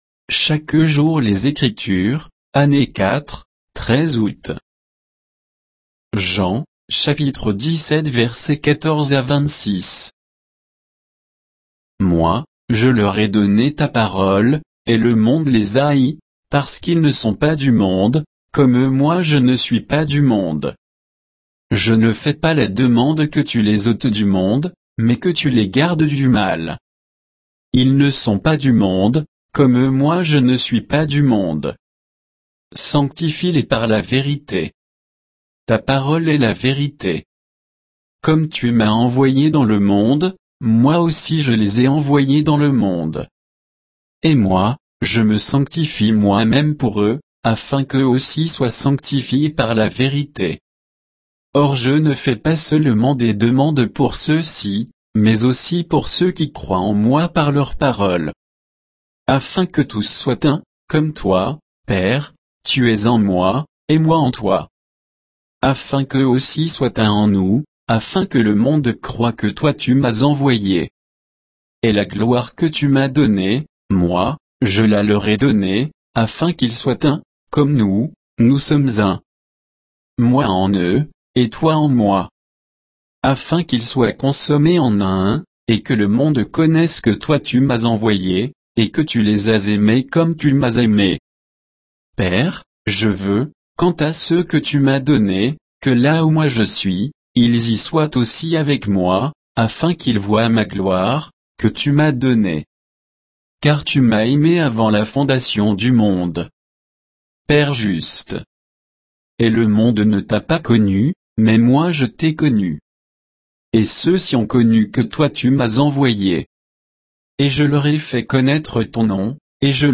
Méditation quoditienne de Chaque jour les Écritures sur Jean 17